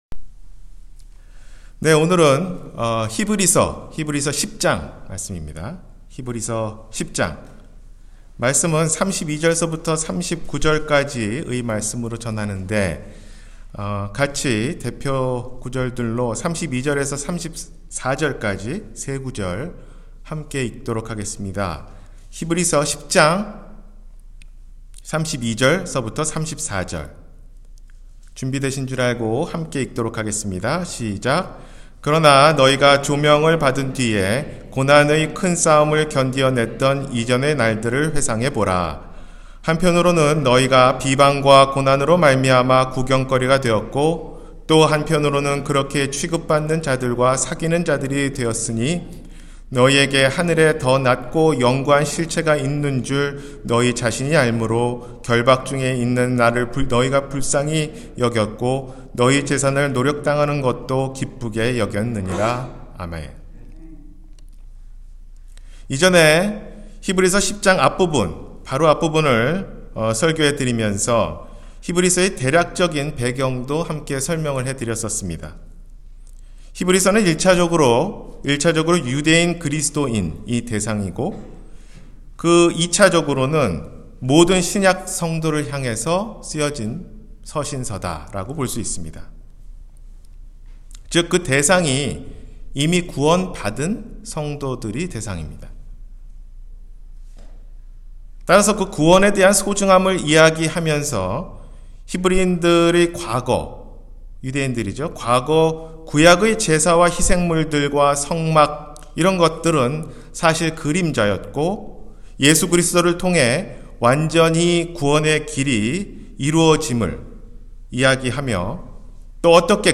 의인은 믿음으로 살리라-주일설교